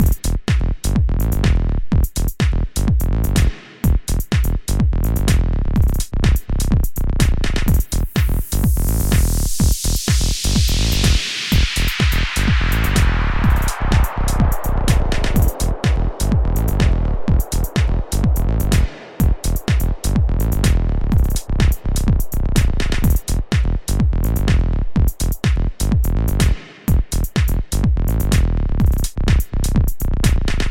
We’re going to use the same input signal to compress the white noise sample that gets introduced at the beginning of the 5th bar
So, listen to the uncompressed white noise sound along with the loop.
electro-loop-whitenoise.mp3